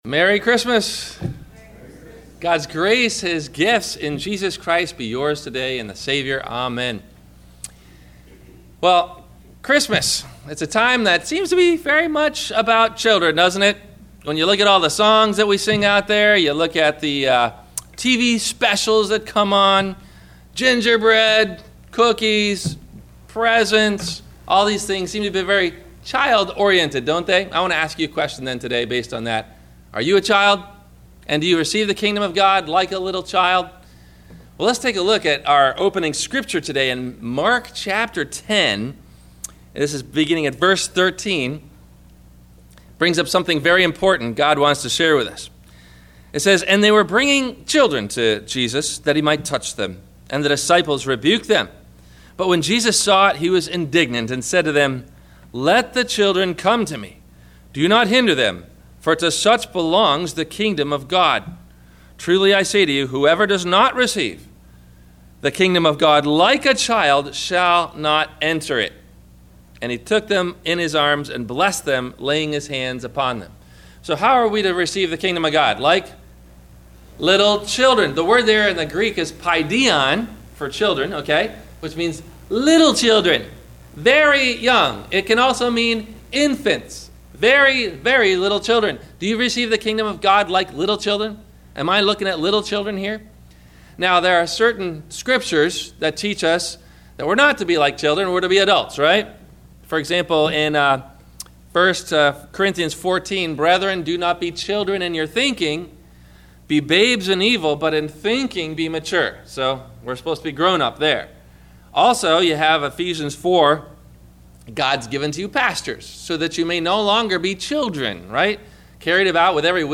Do You Receive The Kingdom of Heaven Like a Child? – Sermon – December 27 2015